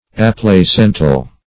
Aplacental \Ap`la*cen"tal\, a.